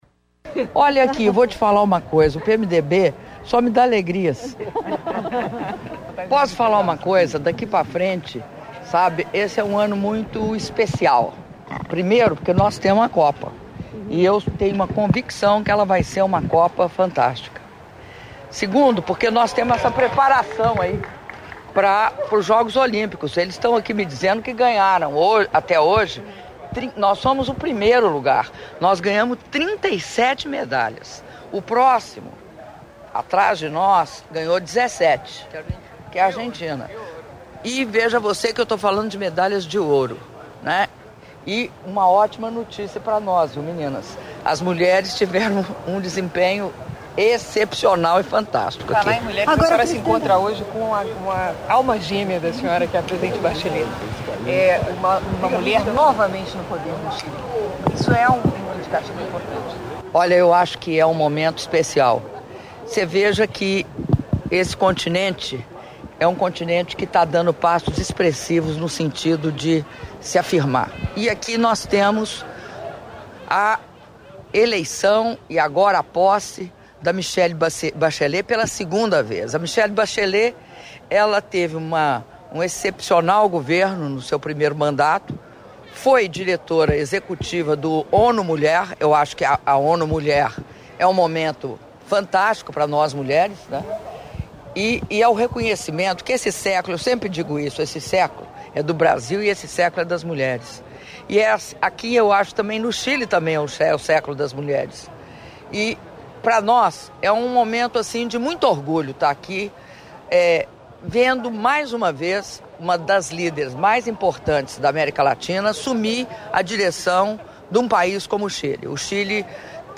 Áudio da entrevista concedida pela Presidenta da República, Dilma Rousseff, após encontro com a Delegação Brasileira nos Jogos Sul-Americanos - Chile (06min49s)